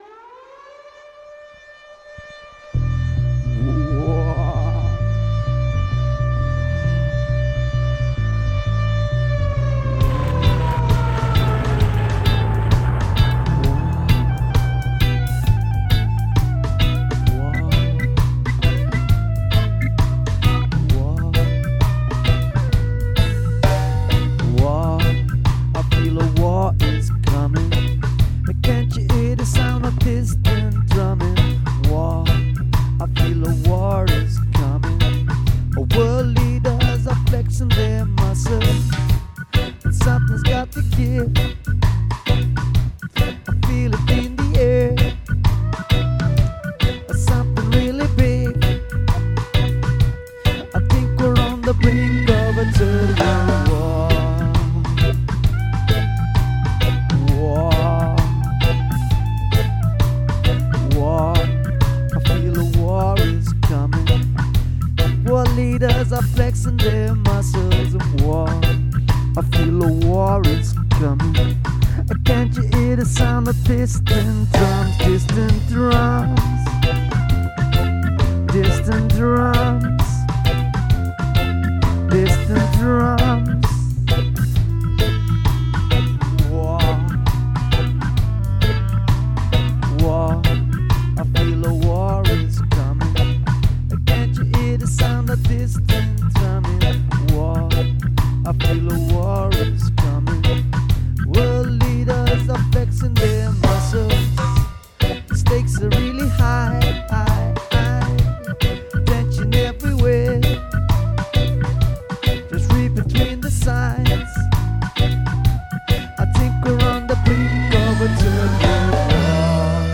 work in progress cho Em Am B ver Bm Cho 2 Em F bridge Em D# A# Bm x 2